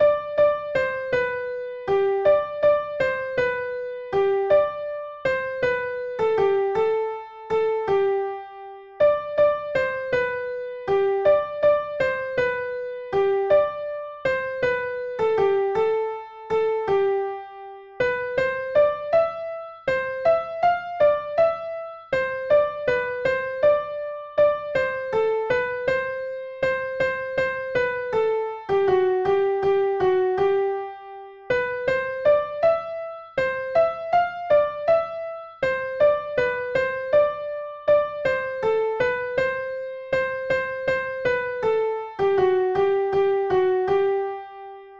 Soprano 1 Part